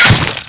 katana_hit1.wav